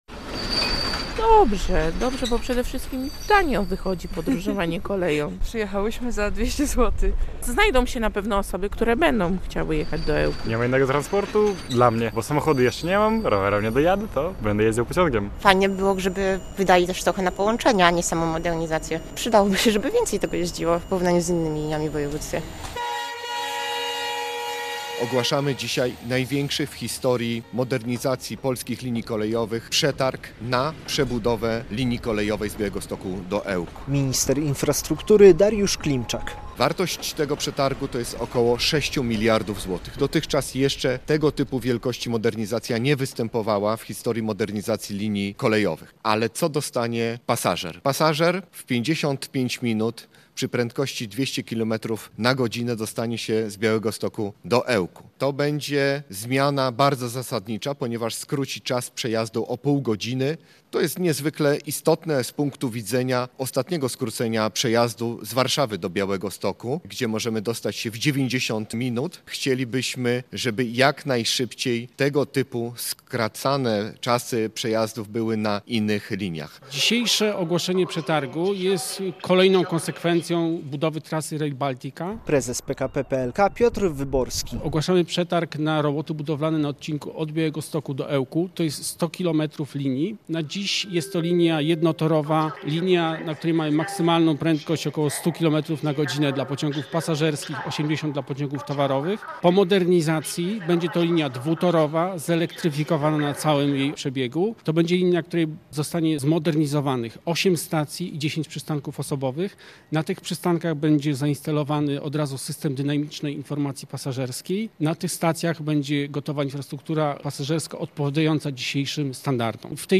To największy przetarg w historii - poinformował w piątek (3.01) w Białymstoku minister infrastruktury Dariusz Klimczak podczas ogłaszania przetargu na ten odcinek Rail Baltica.
Rusza przetarg na modernizację linii kolejowej Białystok-Ełk o wartości ok. 6 mld zł - relacja